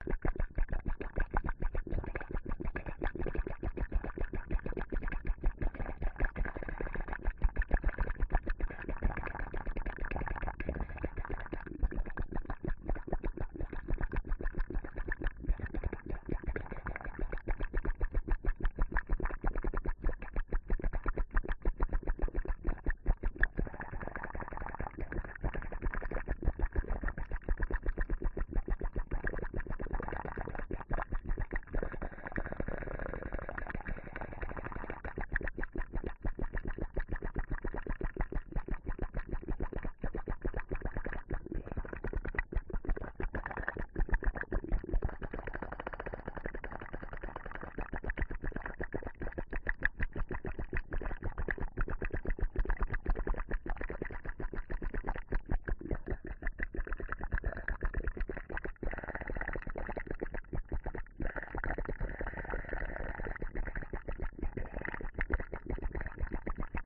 Звуки химии
Химическая реакция с сухим льдом: растворение в желе и воде, пузырьки, версия 18